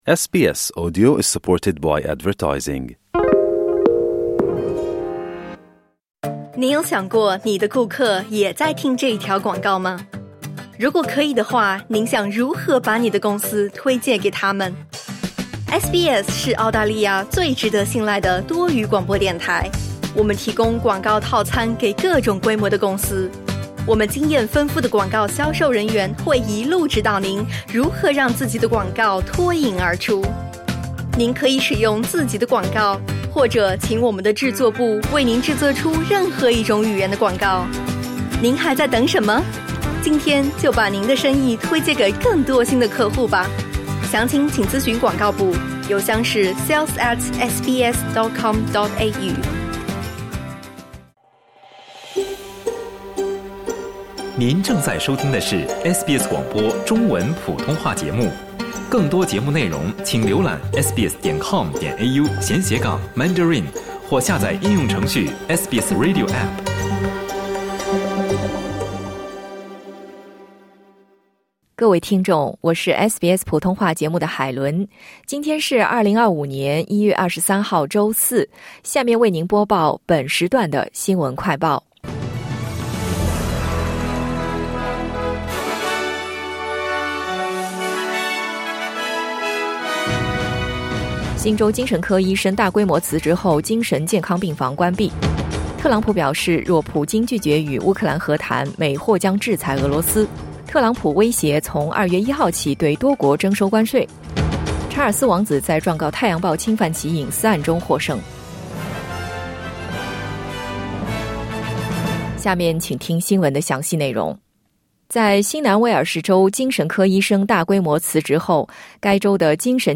【SBS新闻快报】新州精神科医生大规模辞职后精神健康病房已关闭